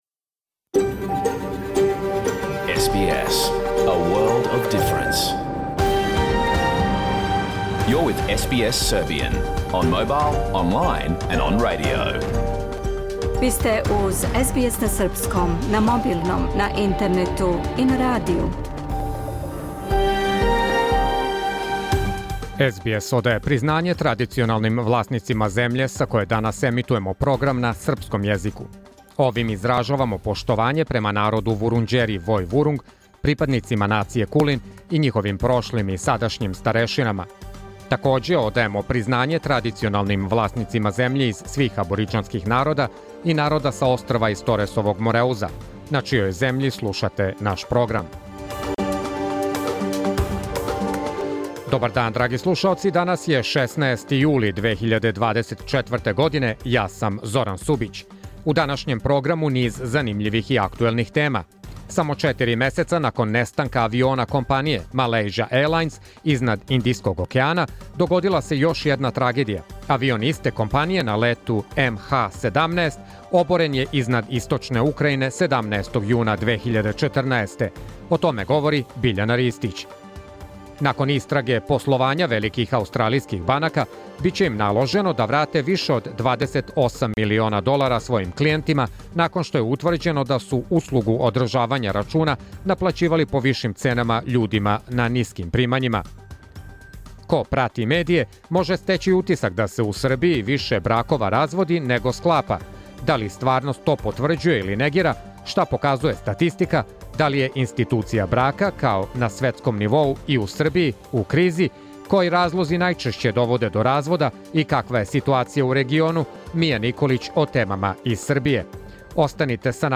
Програм емитован уживо 16. јула 2024. године
Уколико сте пропустили данашњу емисију, можете је послушати у целини као подкаст, без реклама.